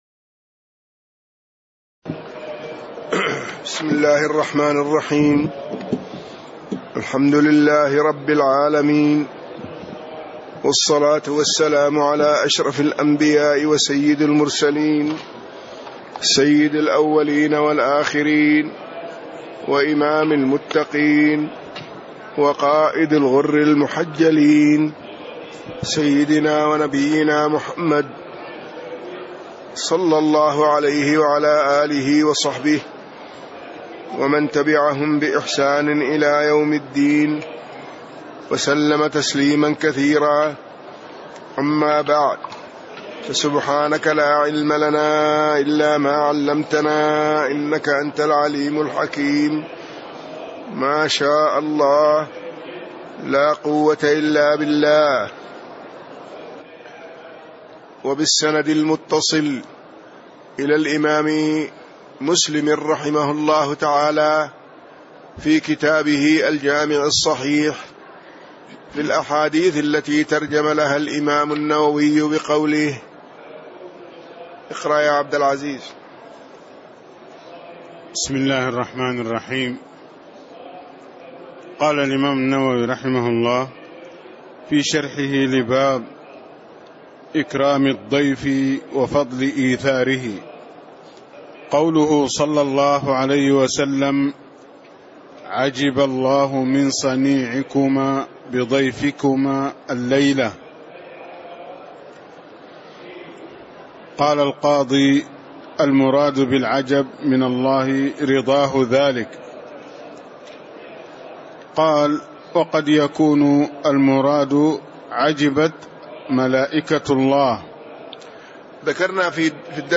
تاريخ النشر ٢٢ شعبان ١٤٣٦ هـ المكان: المسجد النبوي الشيخ